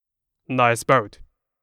ミーム・ネタ系ボイス素材　2
SNS・投稿サイト・バラエティ番組等で見かけるあれやこれやのネタ・コラ画像等フレーズの声素材